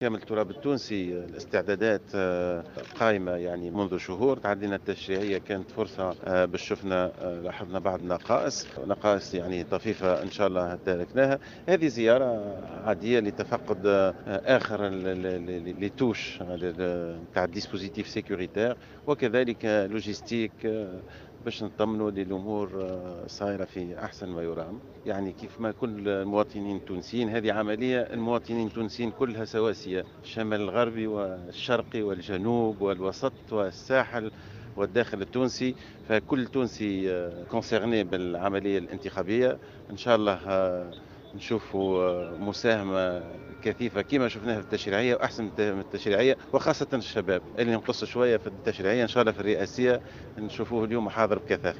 أدى رئيس الحكومة المؤقتة،مهدي جمعة صباح اليوم السبت زيارة ميدانية إلى ولاية باجة للإطلاع على آخر الاستعدادات الأمنية واللوجستية للانتخابات الرئاسية.